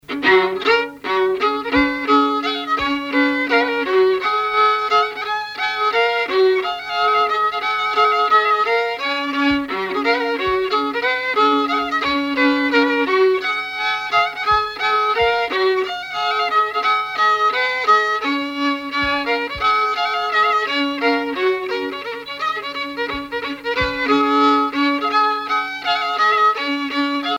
violoneux, violon
musique traditionnelle
Pièce musicale inédite